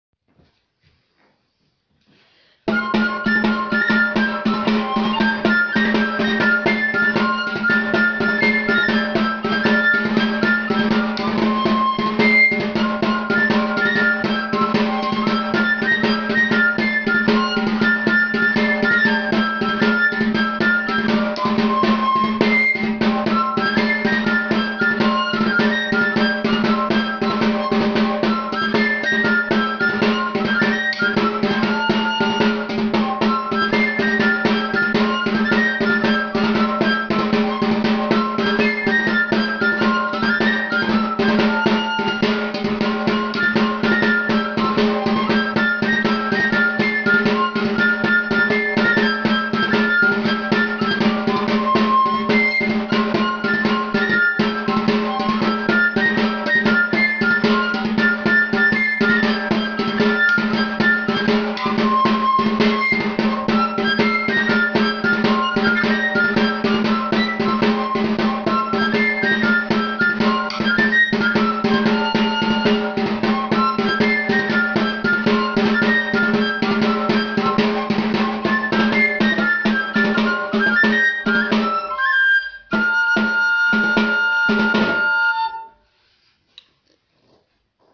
Arantzako dantza luzea: soka-dantza zubirik gabe (mp3)
arantzako-dantza-luzea-soka-dantza-zubirik-gabe-mp3